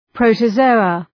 Προφορά
{,prəʋtə’zəʋə} (Ουσιαστικό) ● πρωτόζωα